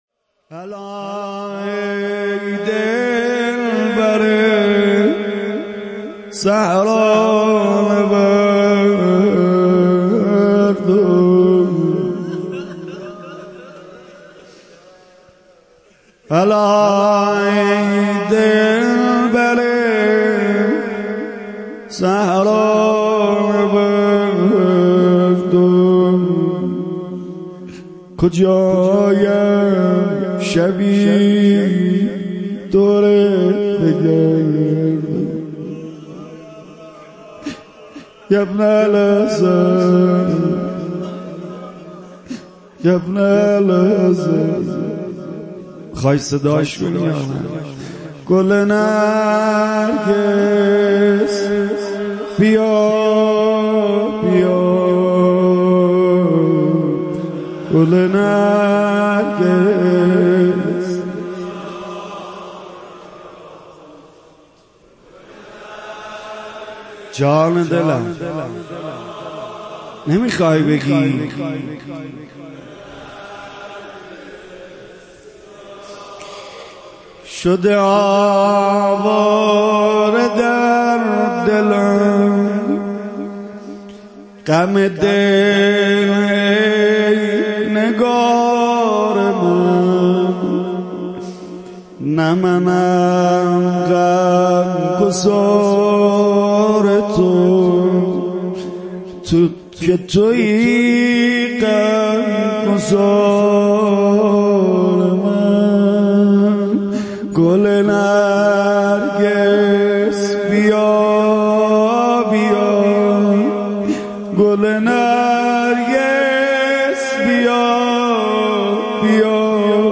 مناجات امام زمان عجل الله تعالی فرجه الشریف با مداحی
چهلمین روز شهادت شهدای حادثه تروریستی زاهدان